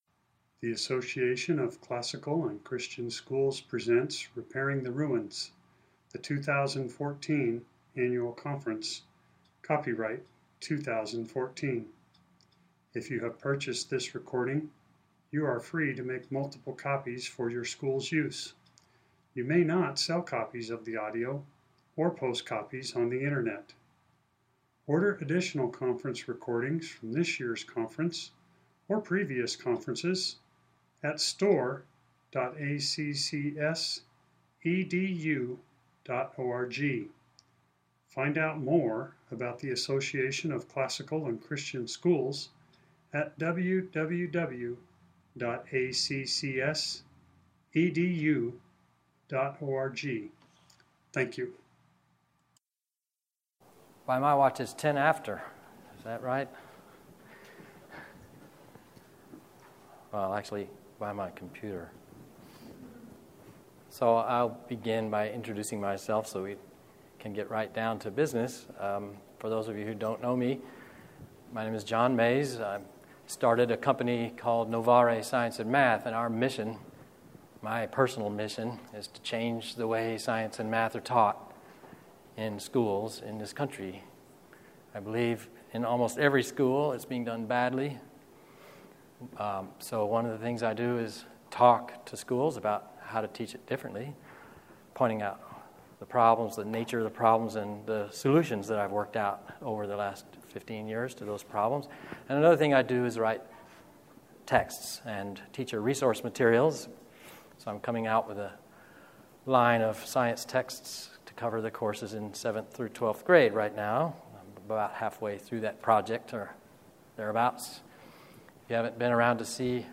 2014 Workshop Talk | 1:06:48 | 7-12, General Classroom